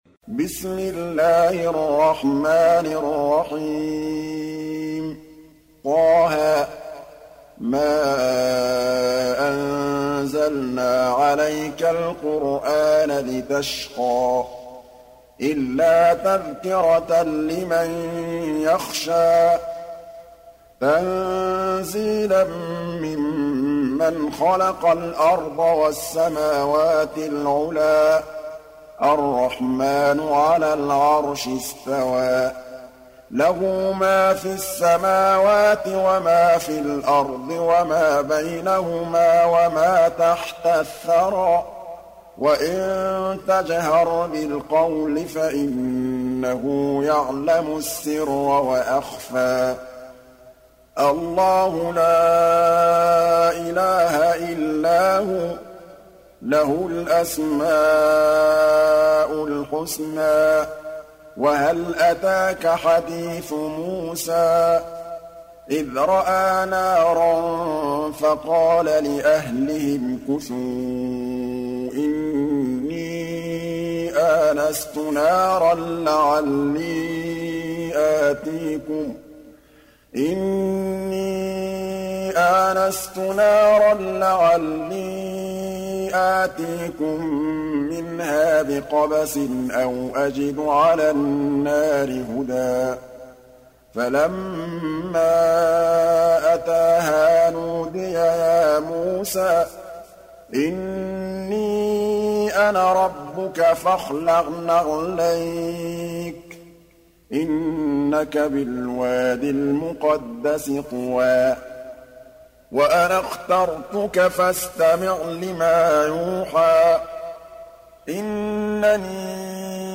Sourate Taha Télécharger mp3 Muhammad Mahmood Al Tablawi Riwayat Hafs an Assim, Téléchargez le Coran et écoutez les liens directs complets mp3